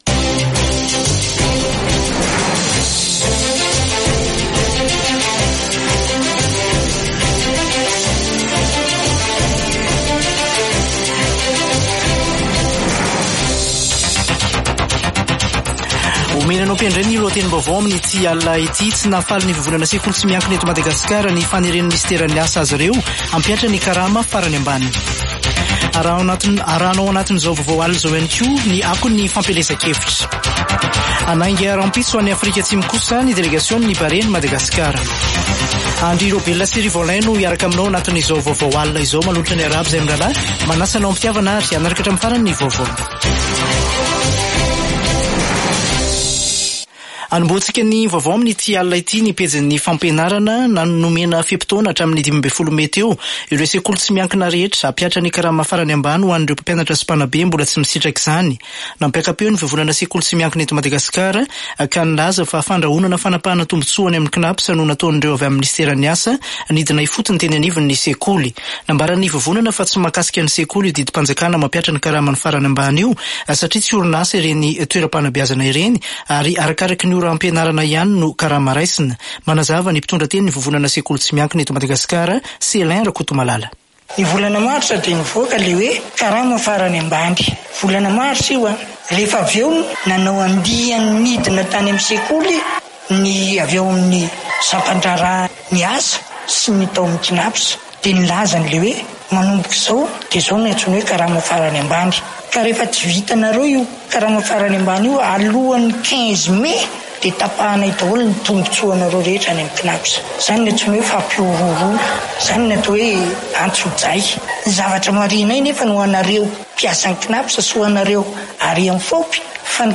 [Vaovao hariva] Alatsinainy 27 mey 2024